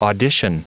Prononciation du mot audition en anglais (fichier audio)
Prononciation du mot : audition